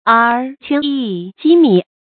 狧穅及米guǐ kǎn jí mǐ成语解释“狧”念shì。
狧穅及米 guǐ kǎn jí mǐ